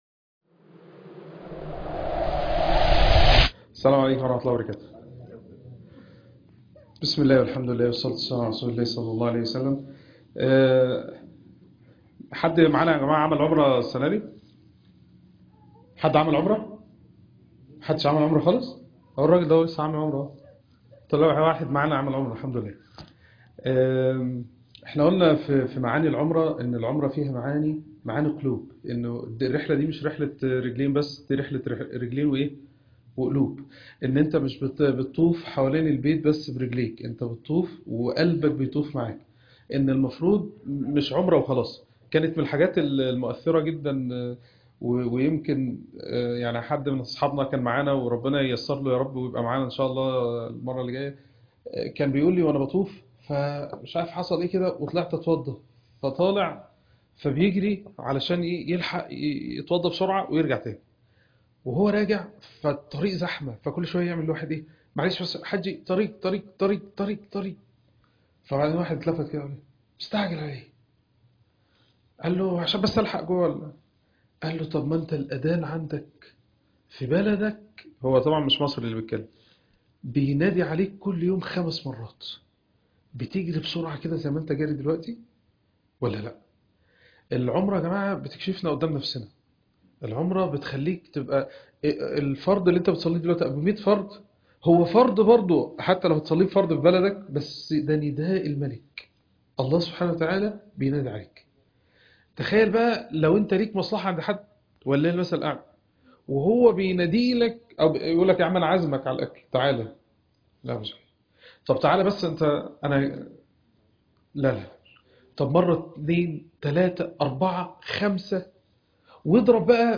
عنوان المادة ماترجعش بإيدك فاضية - الدرس - 01 - بمكة عمرة التربية-6